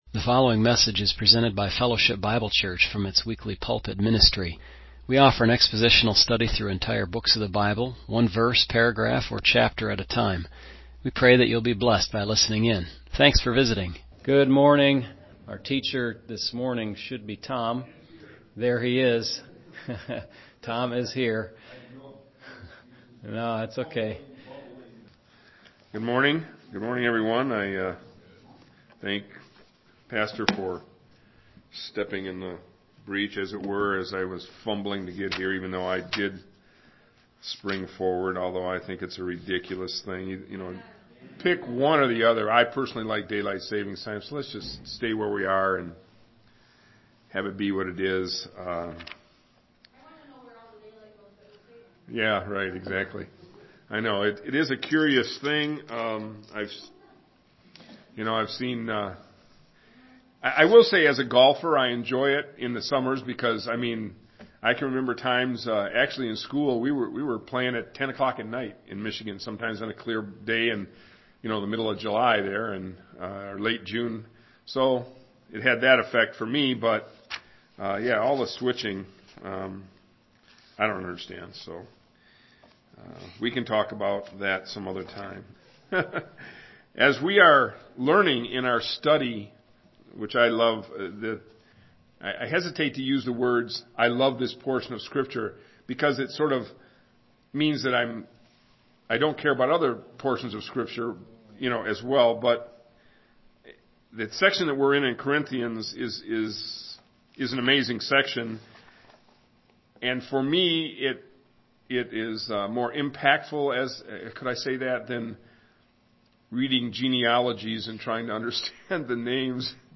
MP3 recordings of sermons and Bible studies for the Sunday ministries at the church.